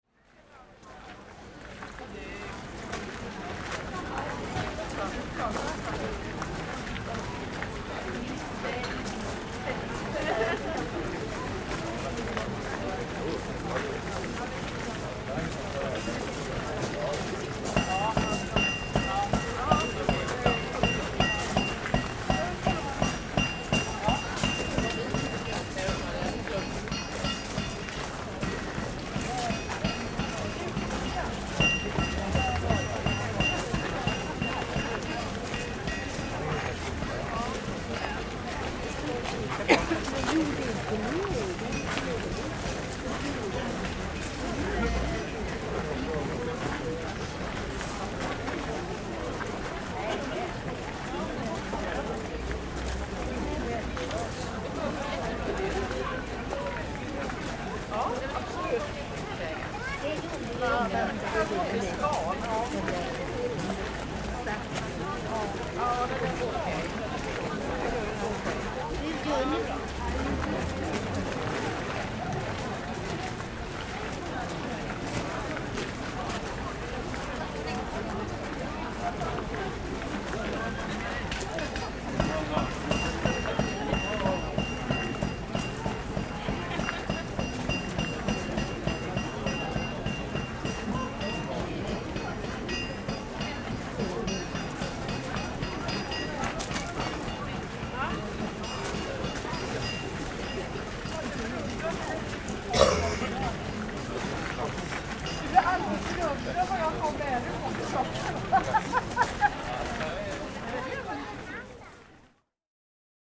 Christmas Market - Old Linkoping
Traditional handcraft in the year 2017. Sounds a Christmas Market in the open air museum "Gamla Linköping.